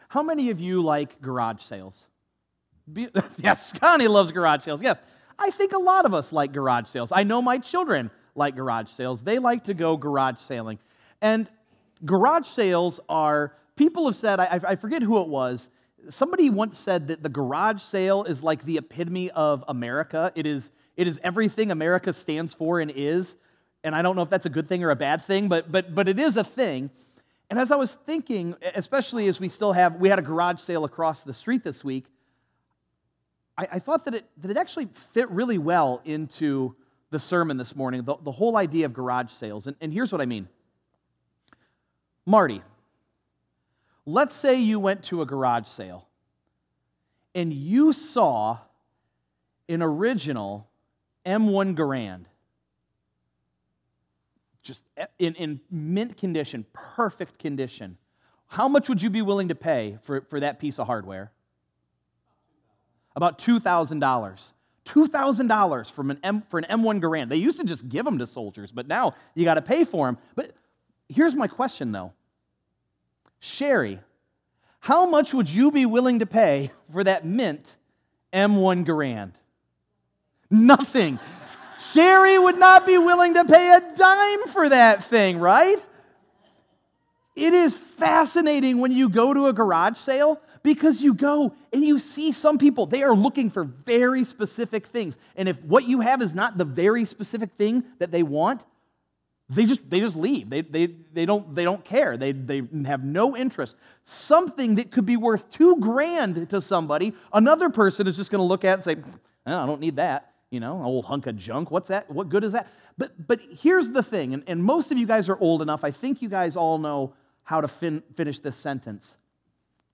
9_22_24_sunday_sermon.mp3